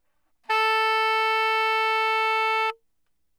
Tenor Saxophone
TenorSaxA4.wav